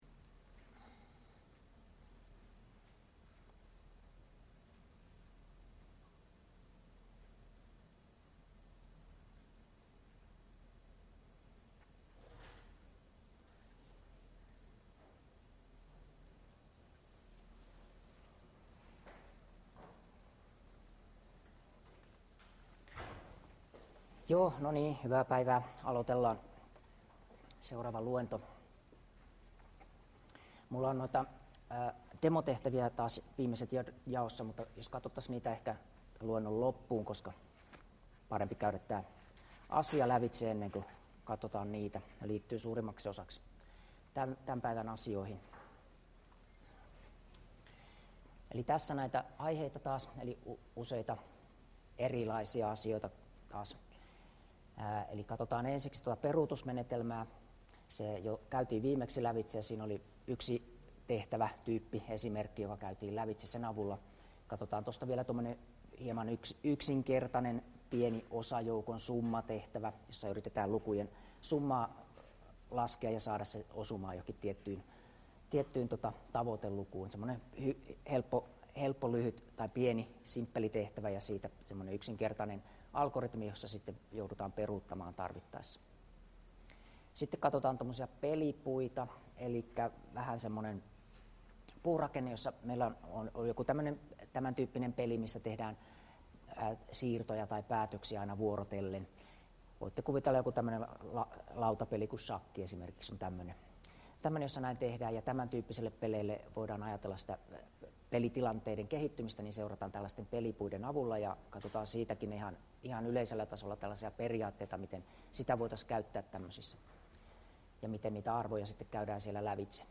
Luento 11 — Moniviestin